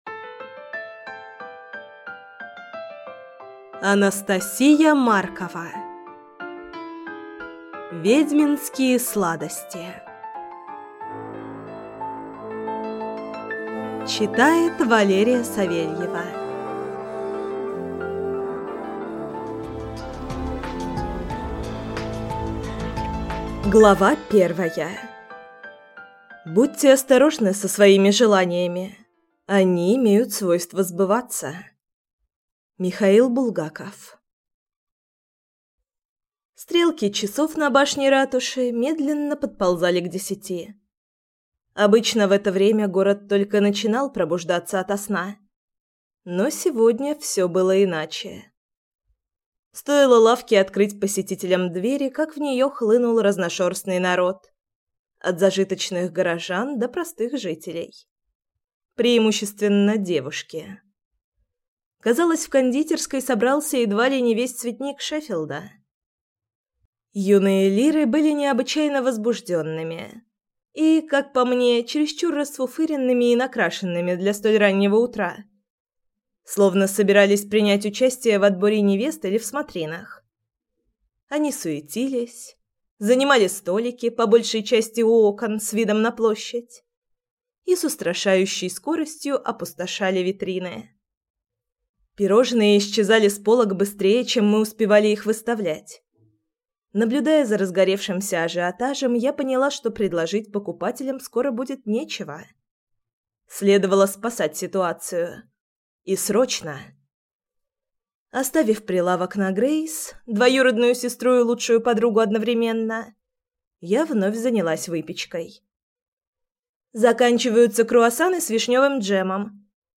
Аудиокнига Ведьминские сладости | Библиотека аудиокниг